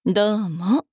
大人女性│女魔導師│店番ボイス